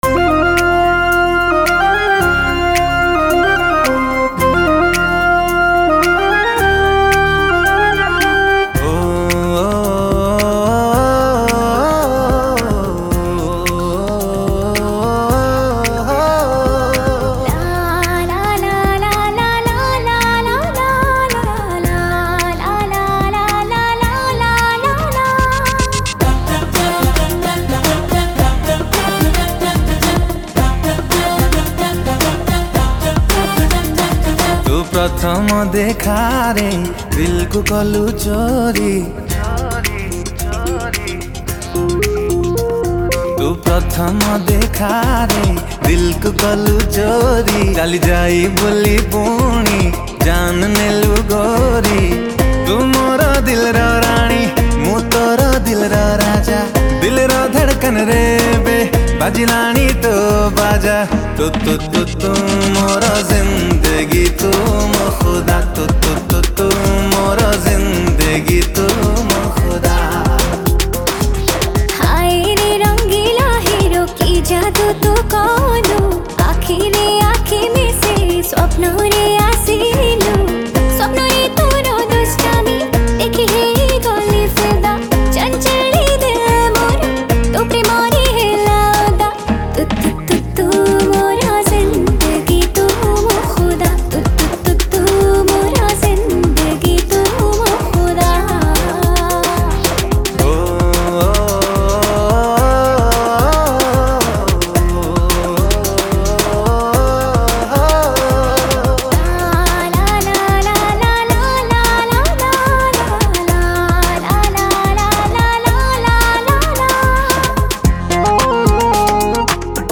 Romantic Song Artist